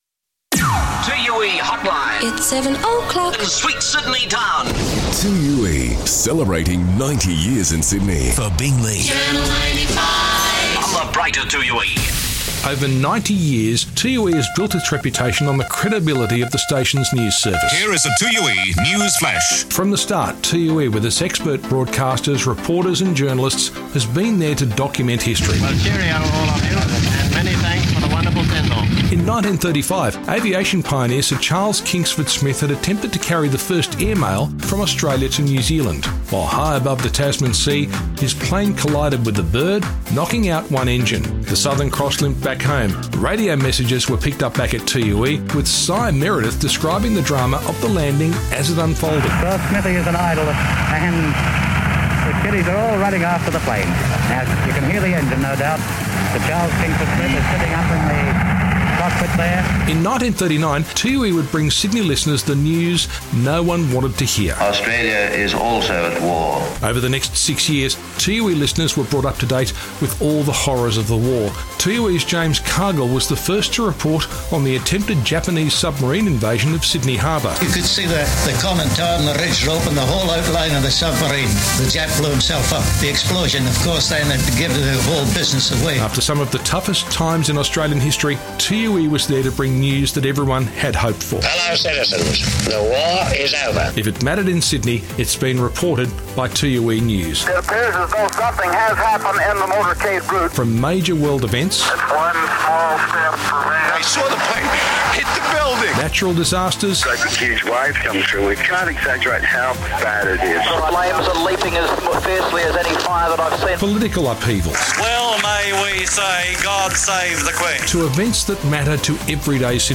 Take a listen back to some classic 2UE flashbacks on 90 years of broadcasting to Sydney and around the globe.